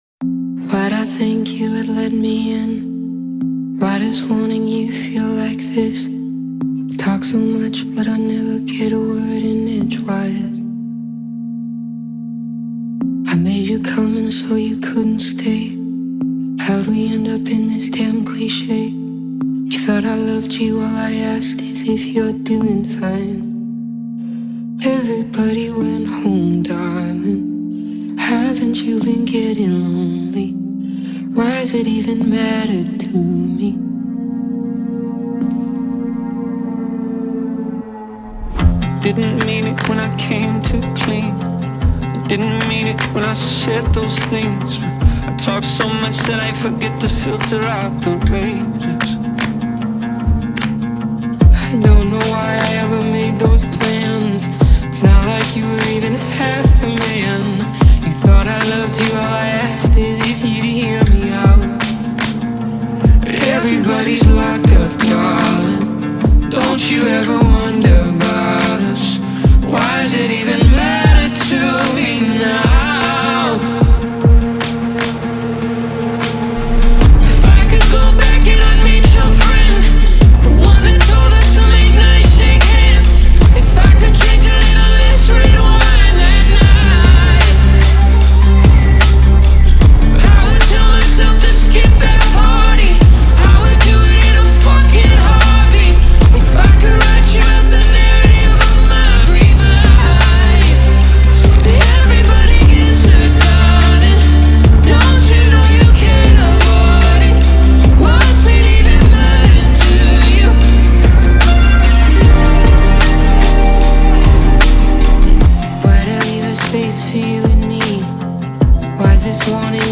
энергичная и мелодичная песня